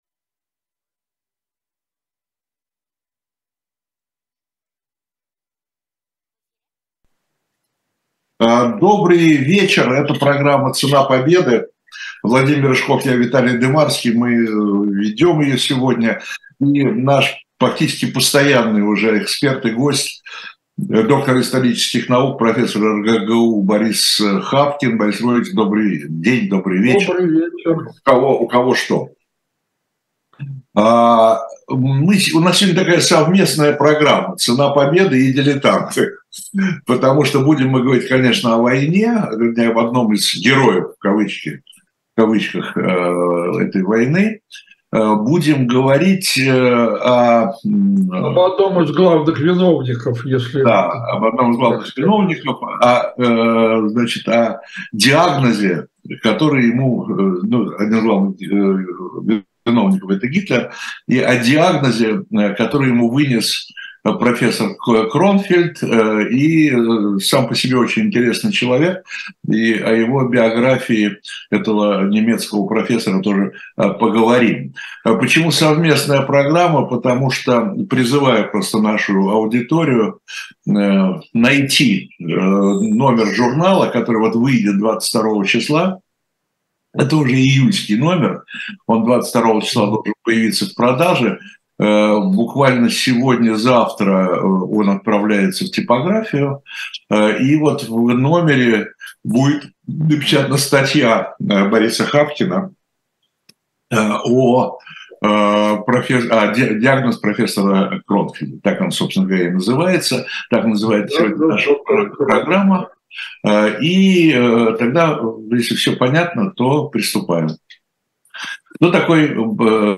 Ведущие: Владимир Рыжков, Виталий Дымарский